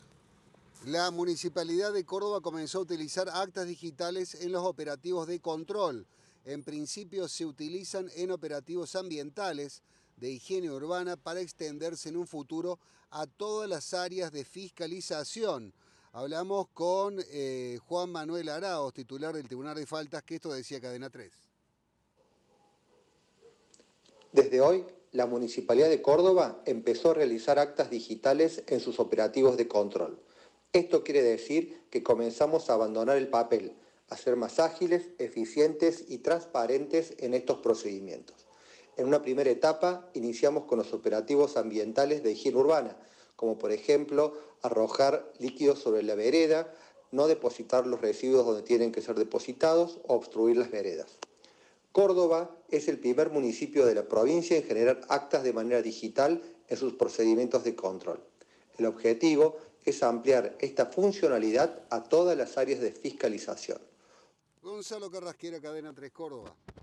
El titular del Tribunal de Faltas, Juan Manuel Aráoz, dialogó con Cadena 3 y explicó : "Desde hoy la Municipalidad de Córdoba empezó a realizar actas digitales en sus operativos de control, esto quiere decir que comenzamos a abandonar el papel, a ser más ágiles, eficientes y transparentes en estos procedimientos".